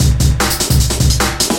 时髦的霹雳鼓 150 bpm
描述：快速和时髦！
Tag: 150 bpm Breakbeat Loops Drum Loops 275.80 KB wav Key : Unknown